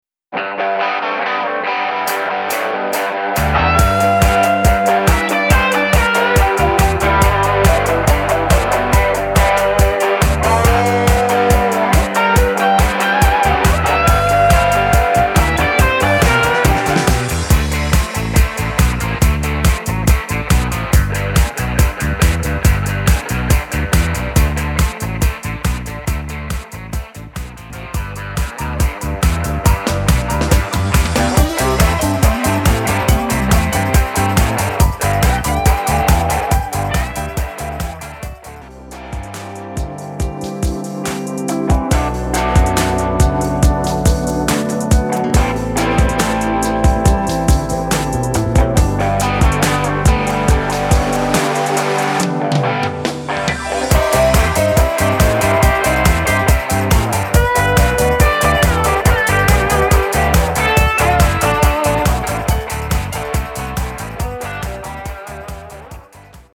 Tonacija: G-dur